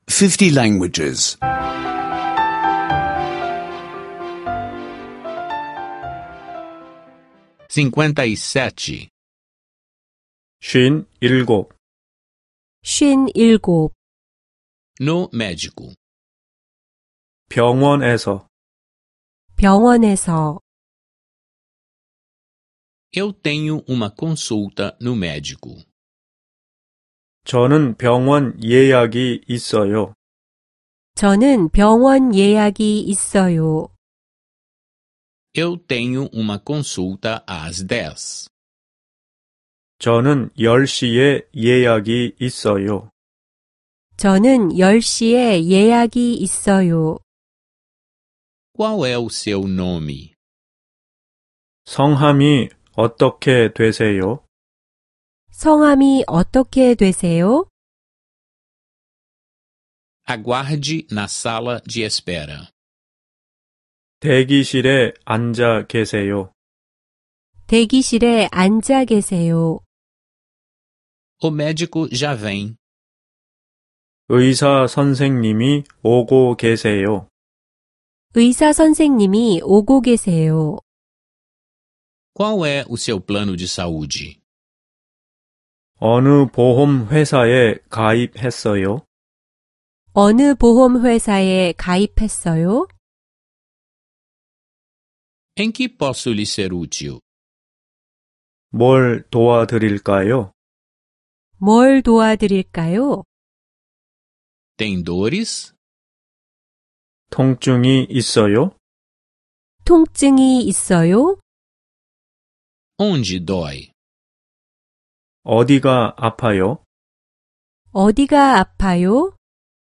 Aulas de coreano em áudio — download grátis